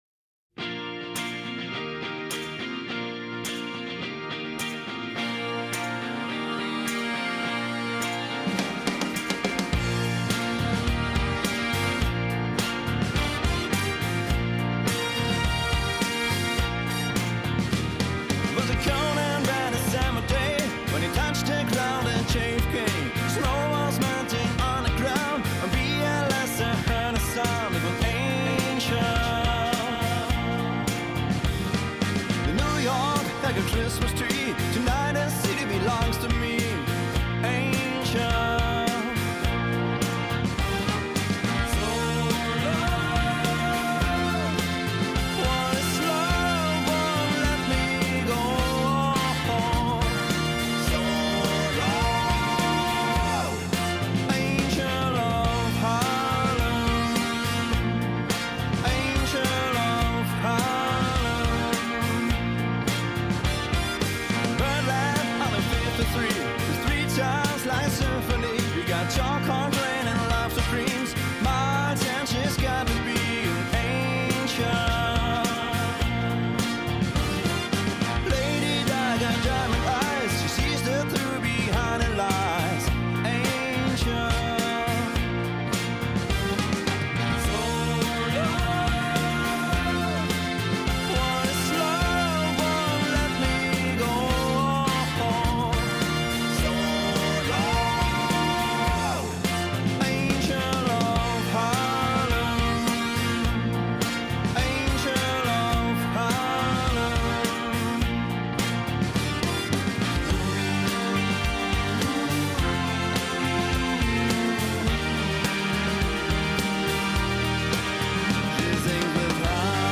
Tanzmusik